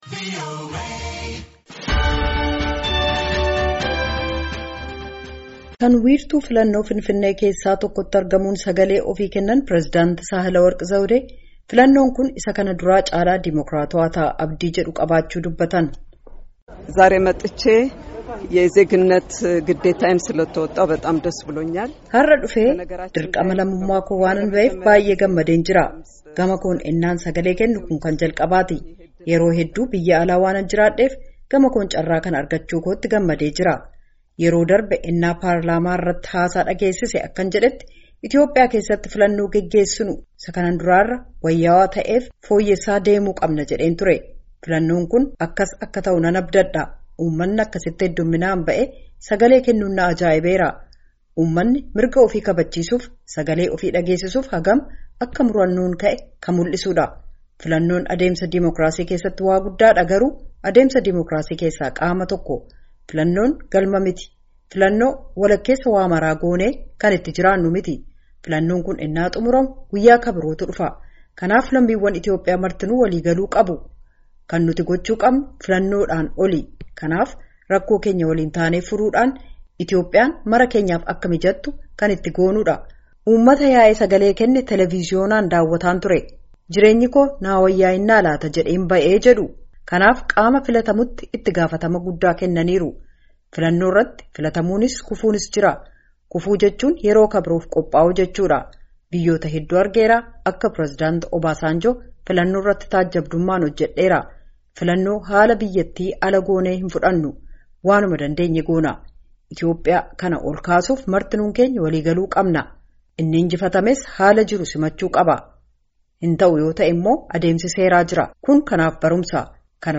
Kan wiirtuu filannoo Finfinnee keessaa tokkotti argamuun sagalee ofii kennan President Sahlework Zewde, filannoon kun isa kana duraa caalaa dimokraatawaa ta’a abdii jedhu qabaachuu dubbatan.